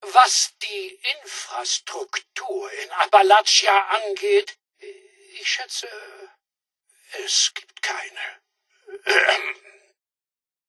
Fallout 76: Audiodialoge